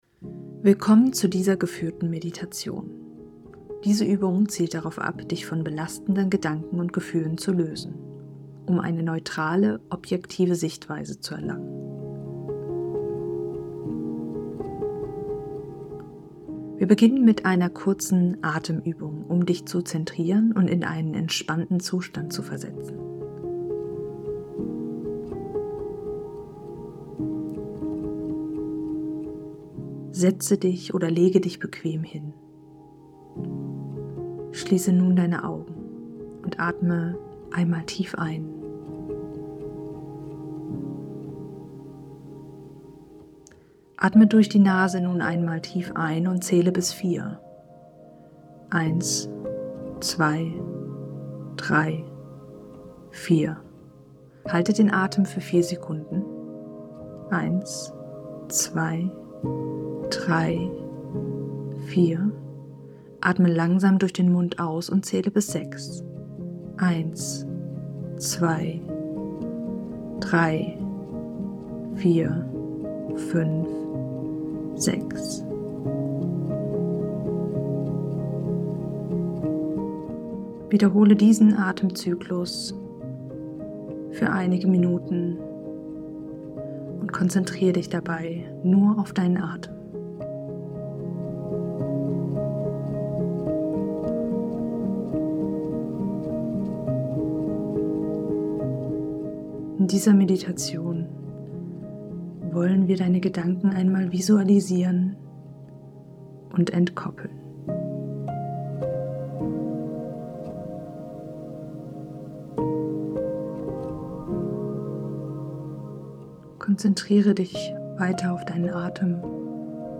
🌟 Geführte Meditation: Tauche ein in eine wohltuende Meditation, die speziell entwickelt wurde, um dir zu helfen, dich von den Gedanken zu distanzieren und deinen Geist zu beruhigen.